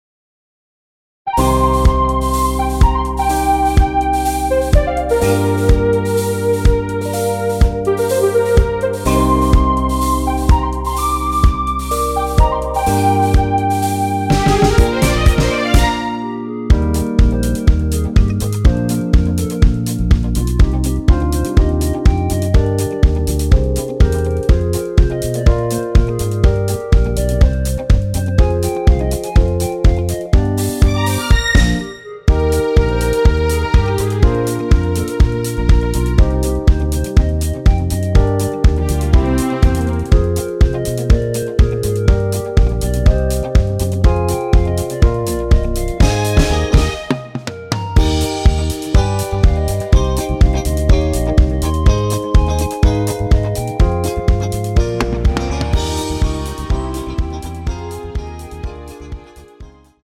원키에서(+3)올린 멜로디 포함된 MR입니다.
Eb
앞부분30초, 뒷부분30초씩 편집해서 올려 드리고 있습니다.
중간에 음이 끈어지고 다시 나오는 이유는